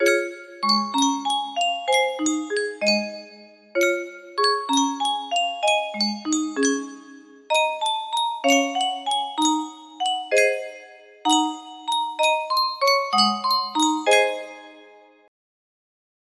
Yunsheng Music Box - Take Me Out to the Ball Game Y104 music box melody
Yunsheng Music Box - Take Me Out to the Ball Game Y104
Full range 60